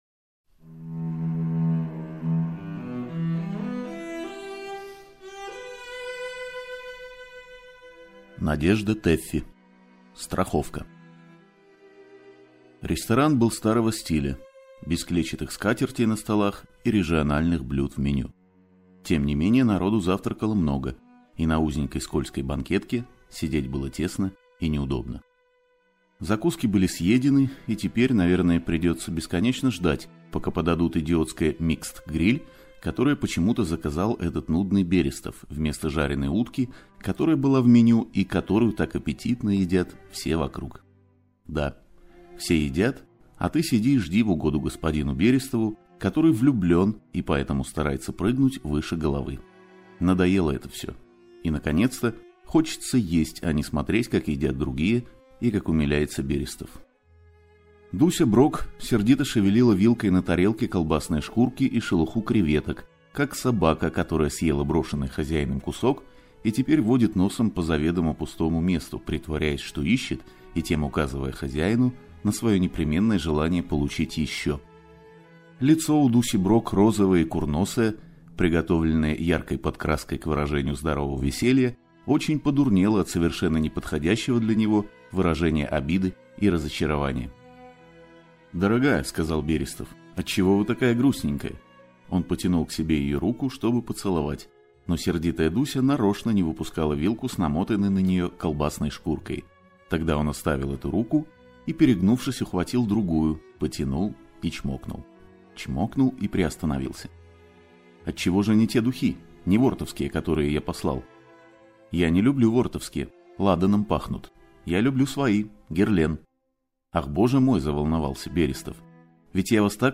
Аудиокнига Страховка | Библиотека аудиокниг